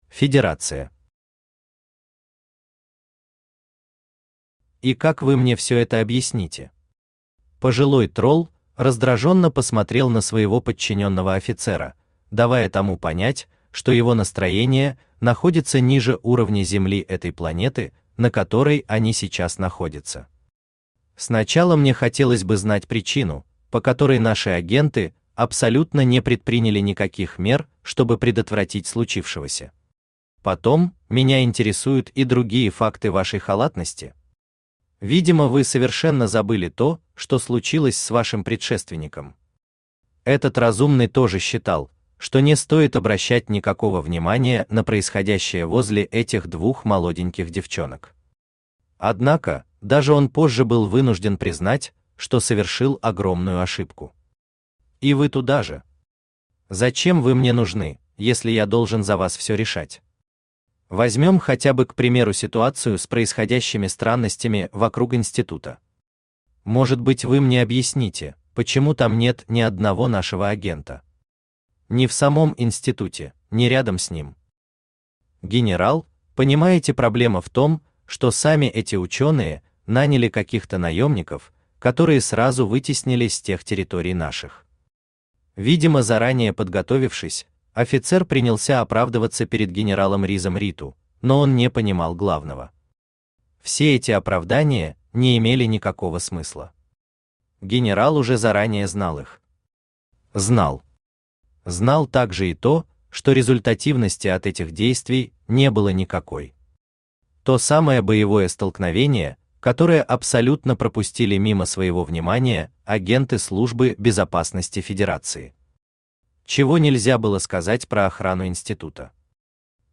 Аудиокнига Дикарь.
Aудиокнига Дикарь. Часть 11. Серый кардинал Автор Хайдарали Усманов Читает аудиокнигу Авточтец ЛитРес.